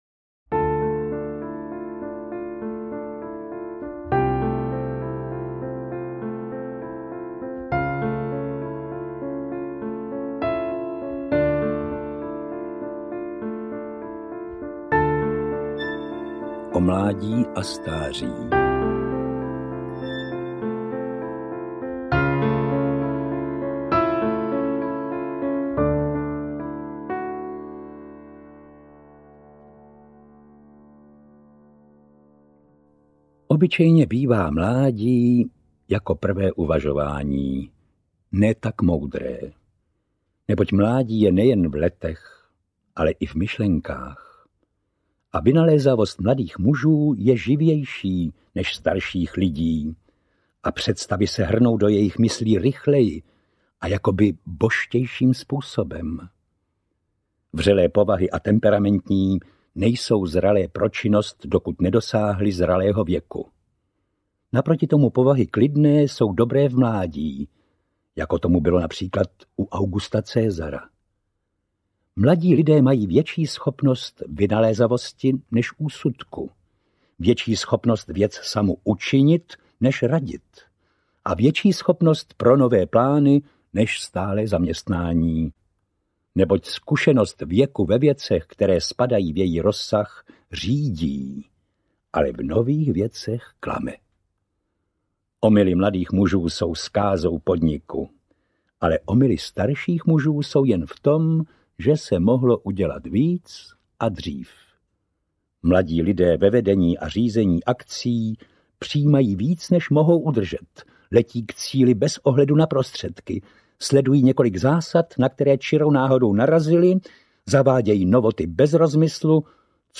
O pravdě a lásce a jiné eseje audiokniha
Studiová nahrávka vznikla ve studio Lucerna podle stejnojmenného představení Lyry Pragensis, které mělo premiéru v roce 1992 v Lobkovickém paláci na Pražském hradě.
• InterpretVladimír Ráž